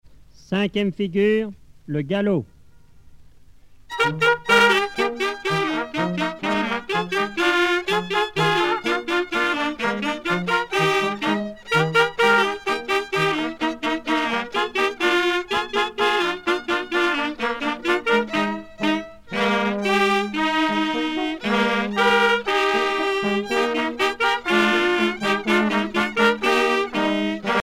danse : quadrille : galop
groupe folklorique
Pièce musicale éditée